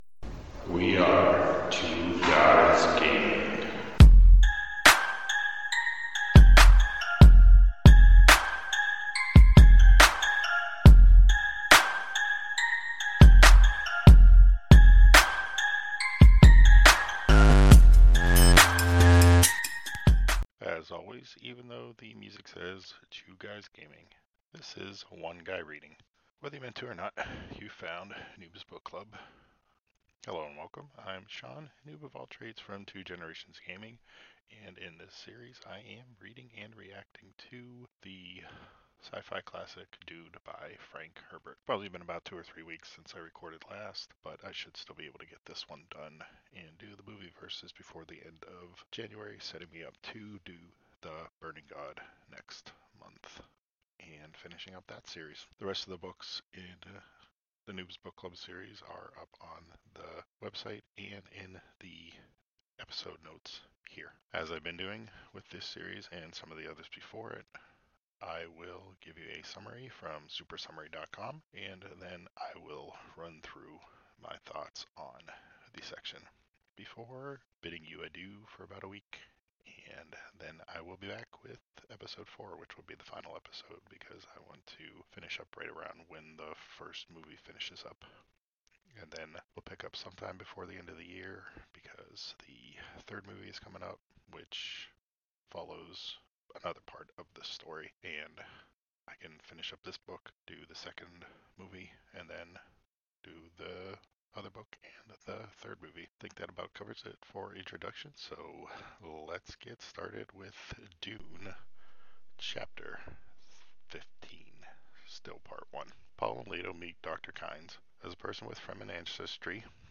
reads and reacts to Chapters 15 through 19 in Dune Episode 3.